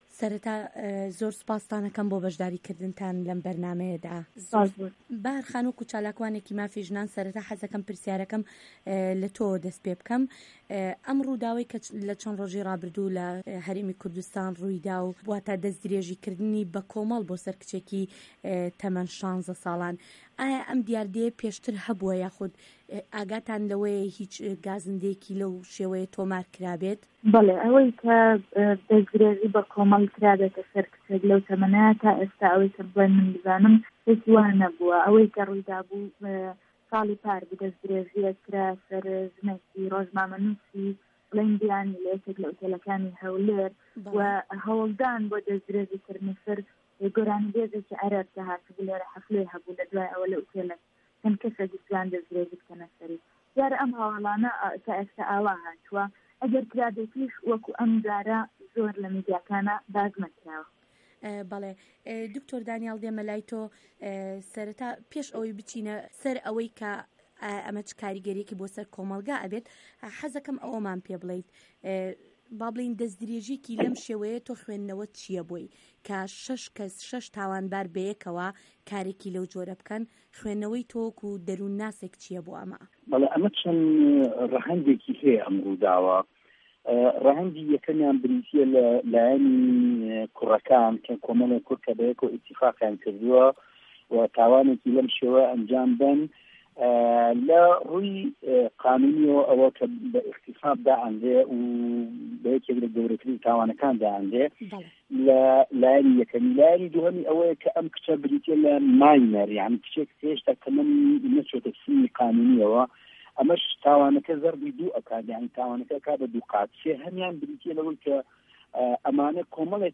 مێزگرد: کاردانه‌وه‌ی ئاکامی ده‌سـتدرێژیـیه‌کی سێـکسی بۆ سه‌ر کچێـک له‌ هه‌رێمی کوردسـتانی عێراقدا